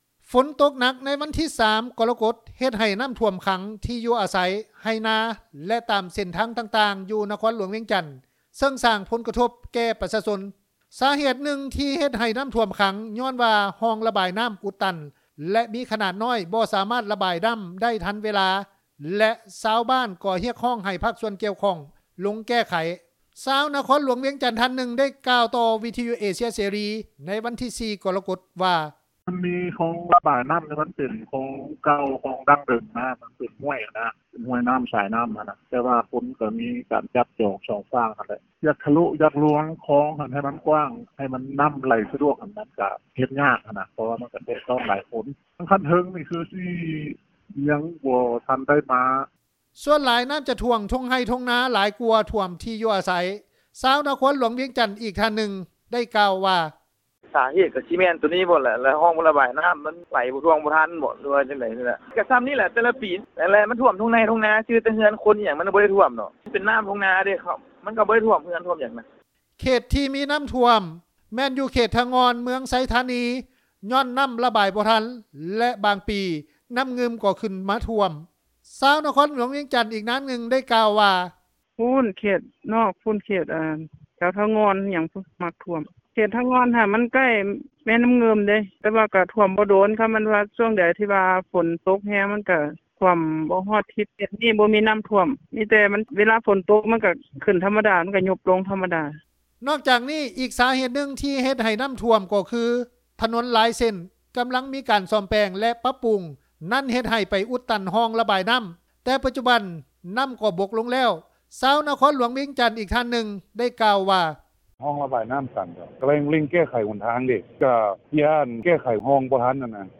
ຊາວຄອນຫລວງວຽງຈັນ ທ່ານໜຶ່ງ ໄດ້ກ່າວຕໍ່ ວິທຍຸເອເຊັຽເສຣີ ໃນວັນທີ 4 ກໍລະກົດວ່າ:
ຊາວນະຄອນຫລວງວຽງຈັນ ອີກນາງໜຶ່ງ ໄດ້ກ່າວວ່າ:
ເຈົ້າໜ້າທີ່ນະຄອນຫລວງວຽງຈັນ ທ່ານໜຶ່ງ ໄດ້ກ່າວວ່າ: